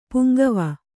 puŋgava